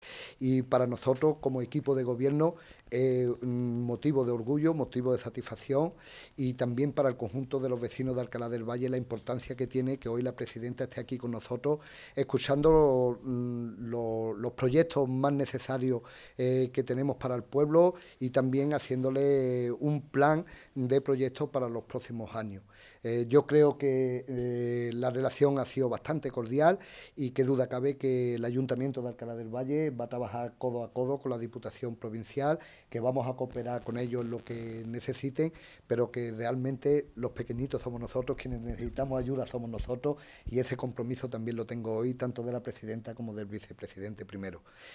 Alcala-del-Valle_alcalde.MP3